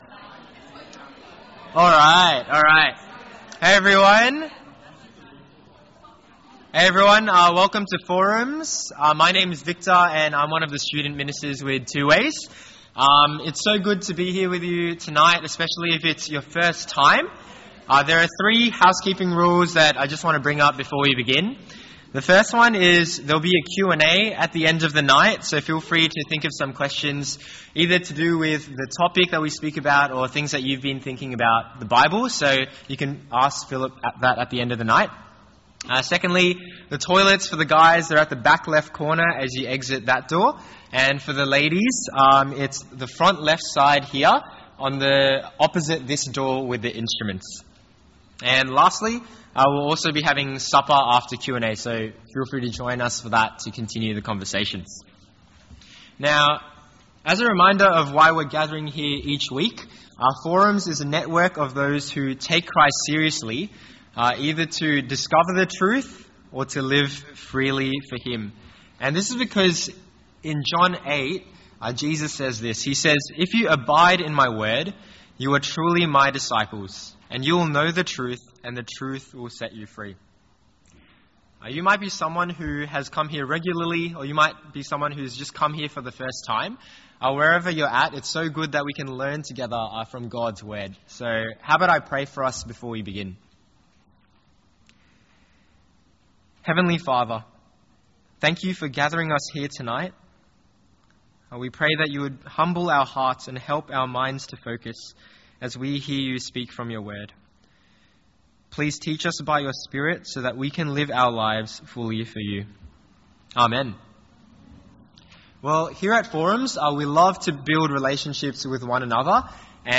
An edited audio version of Week 2, Term 2 of Forum 2025. Forum is a 2 hour session of interactive Bible teaching for 18-30 year olds.